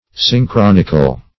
Search Result for " synchronical" : The Collaborative International Dictionary of English v.0.48: Synchronical \Syn*chron"ic*al\, a. [Cf. F. synchronique.]